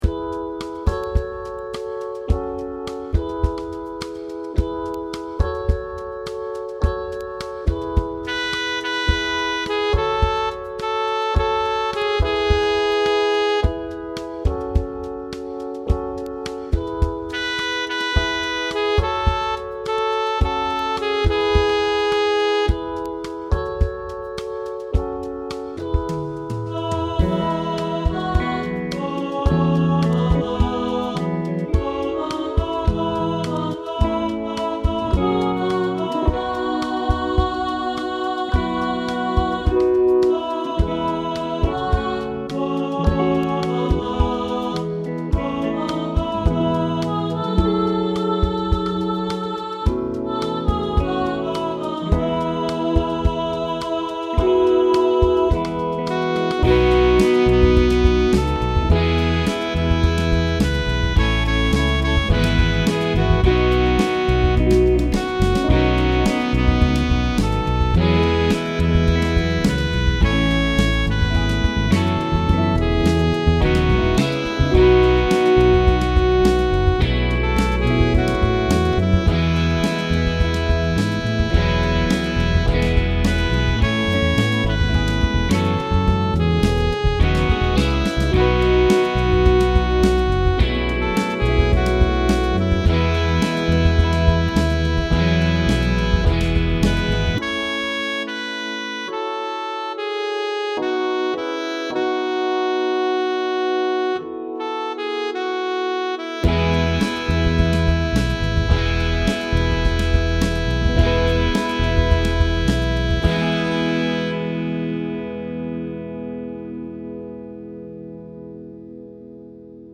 Hosanna_sopraan
Hosanna_sopraan.mp3